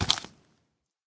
step3.ogg